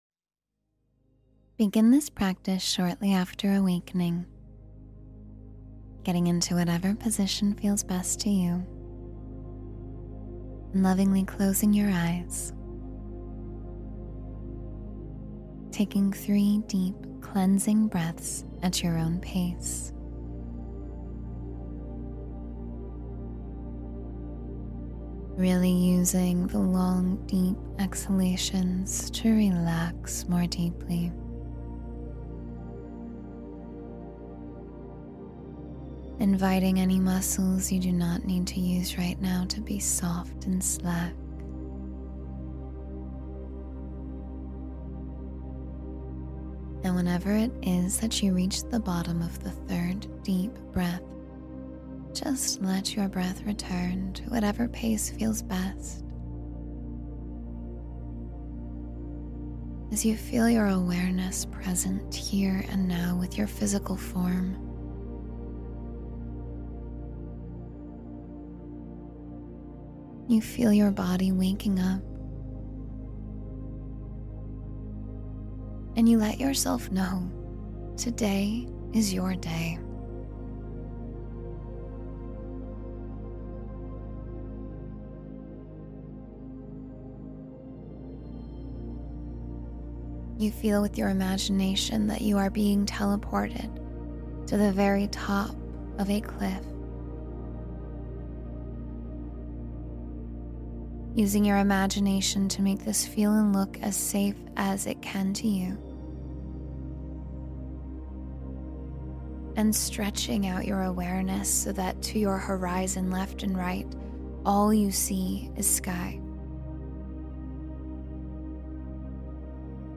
Feel Your Power in 10 Minutes — Meditation for Empowerment and Strength